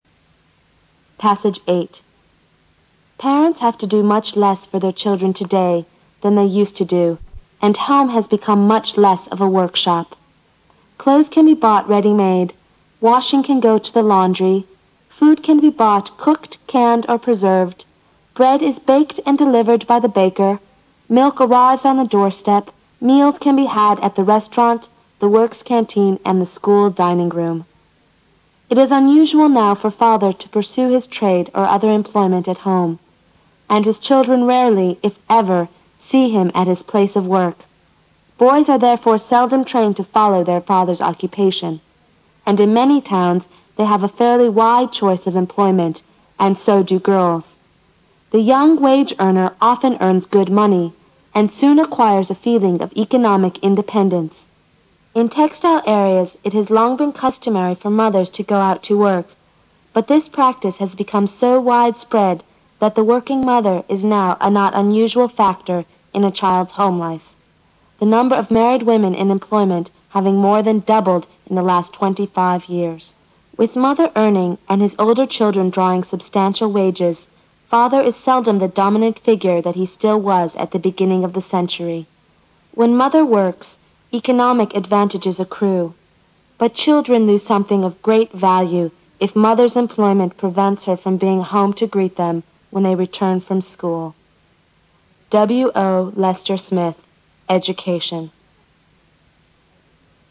新概念英语85年上外美音版第四册 第8课 听力文件下载—在线英语听力室